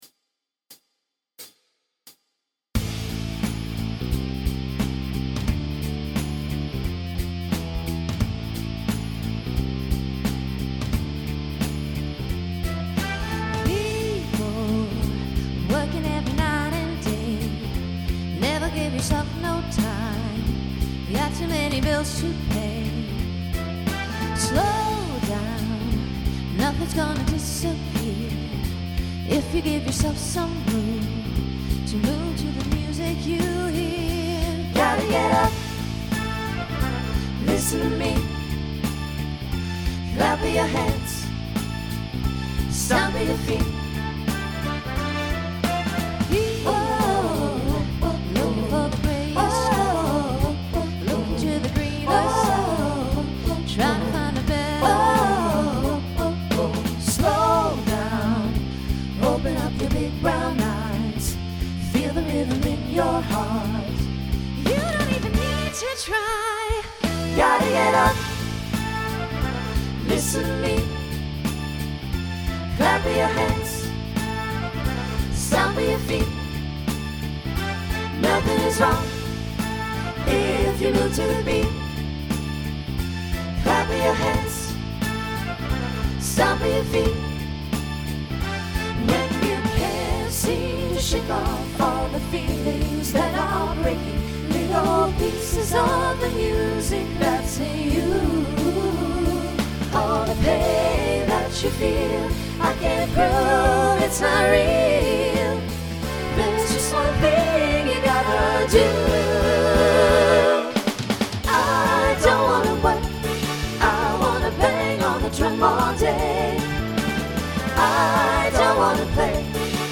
Voicing SATB Instrumental combo Genre Pop/Dance , Rock
2010s Show Function Mid-tempo